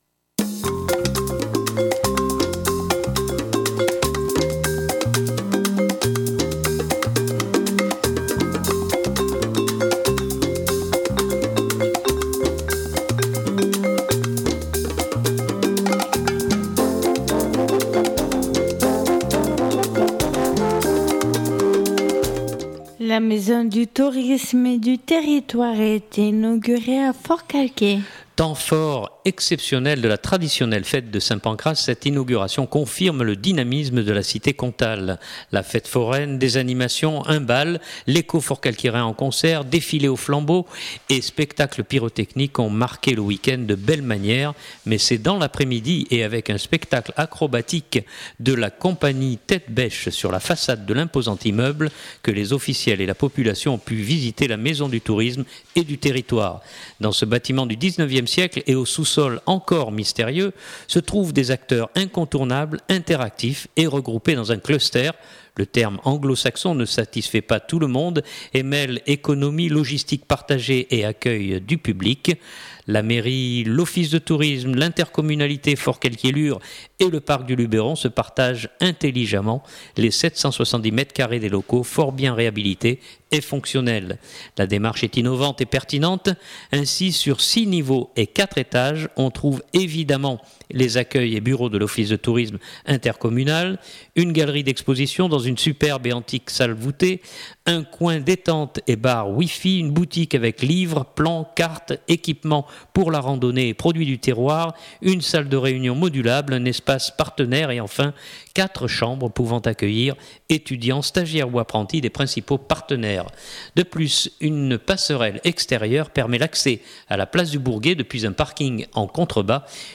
Je vous propose d’écouter plusieurs personnalités présentes à Forcalquier samedi pour cet événement. La Préfète des Alpes de Haute-Provence Patricia Willaert, séduite par l’outil, puis le Président du Parc du Luberon, Jean-Louis Joseph, partenaire de la première heure du projet et le Président du Comité Régional du Tourisme Bernard Jaussaud. Mais d’abord Christophe Castaner, député-maire heureux, d’autant plus que ce dossier fit couler beaucoup d’encre et de salive durant les élections municipales de l’an dernier.